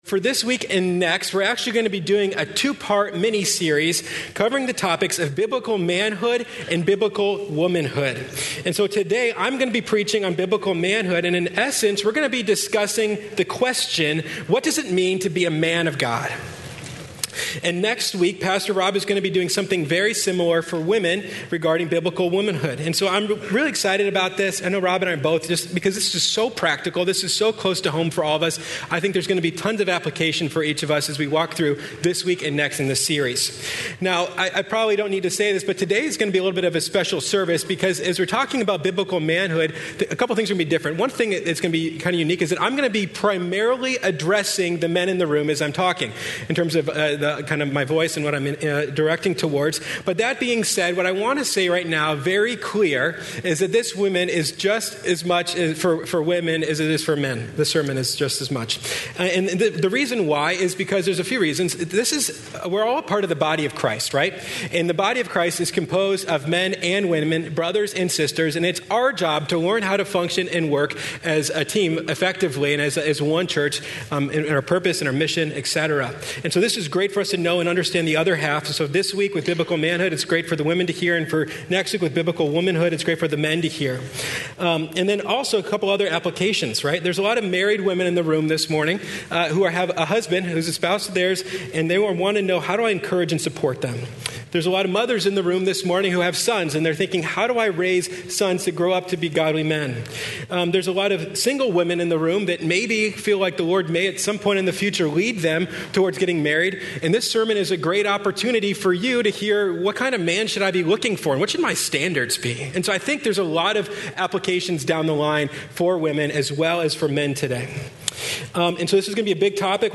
Westgate Chapel Sermons May 5th - Biblical Manhood May 05 2019 | 00:50:10 1x 00:00 / 00:50:10 Subscribe Share Apple Podcasts Overcast RSS Feed Share Link Embed